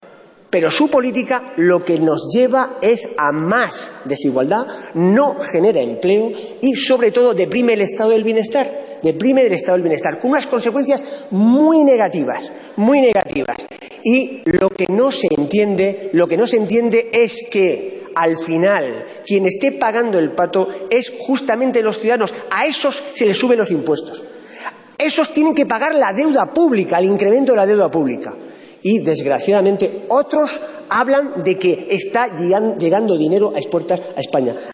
Pedro Saura en el debate de las enmiendas a los presupuestos de 2014.